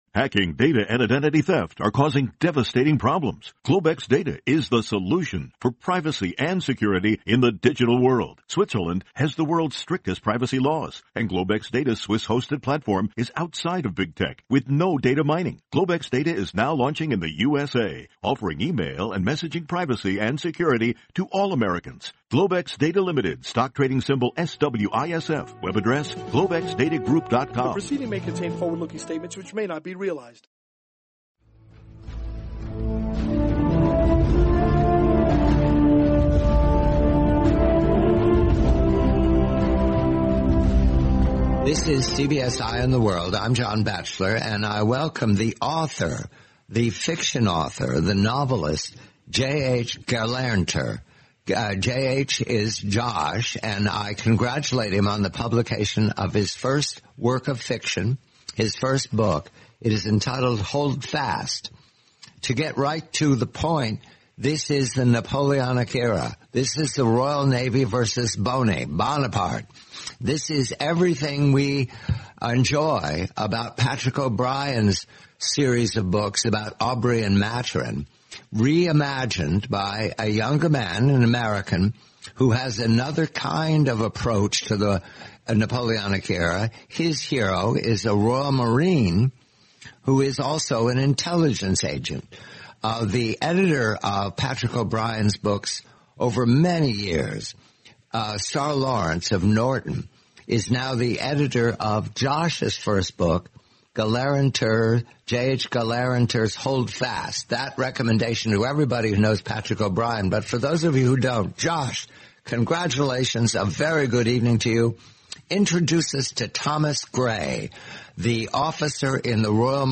Napoleonic-era espionage. The complete, twenty-minute interview.